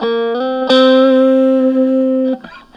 Track 02 - Guitar Lick 05.wav